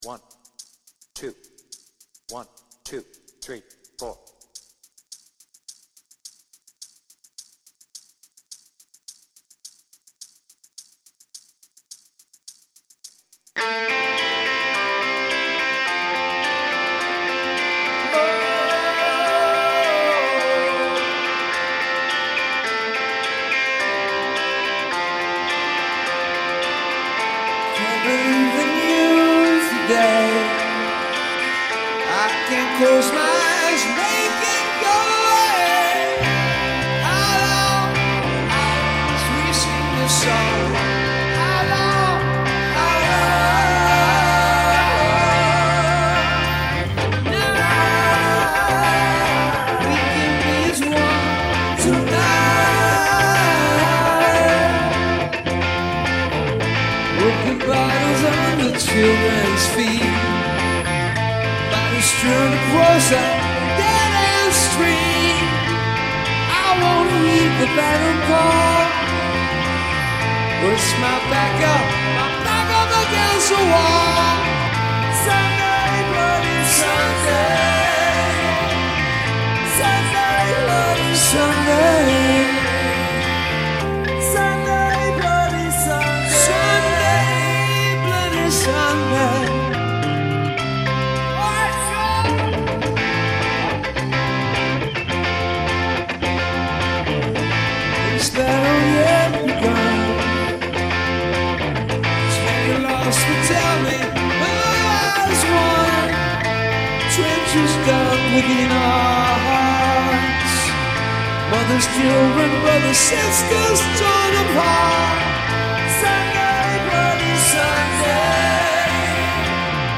BPM : 106
Tuning : Eb
Based on the 360° version + extended outro